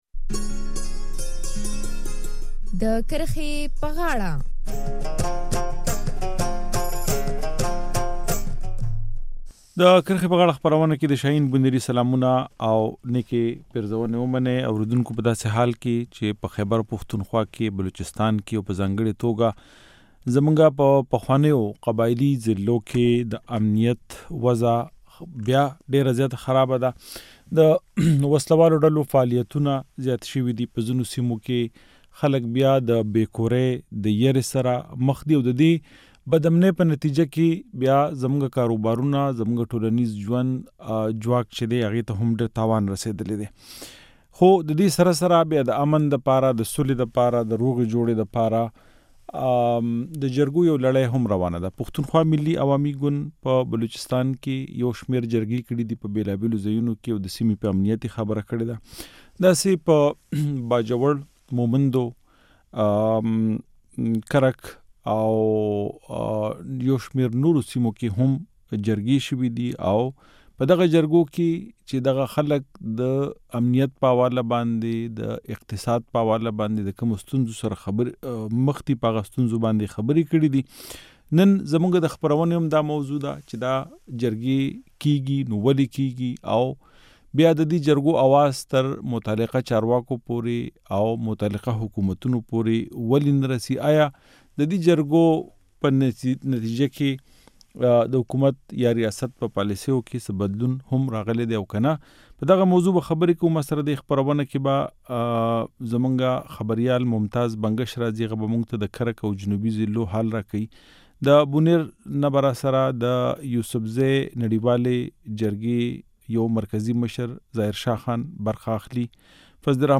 نن پرې د کرښې په غاړه کې بحث کوو.